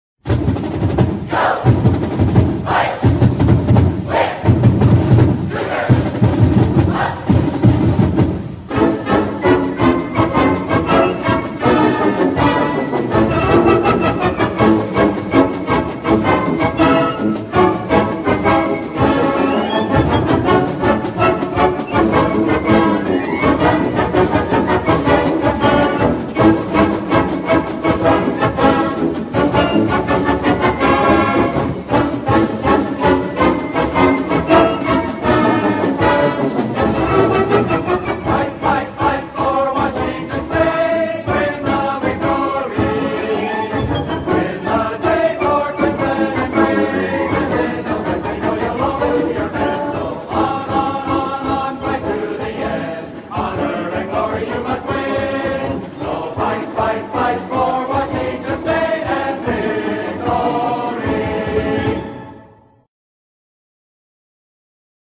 Butch the Cougar cartoon with sousaphoneListen to the Cougar Marching Band playing
WSU Fight Song (live recordings).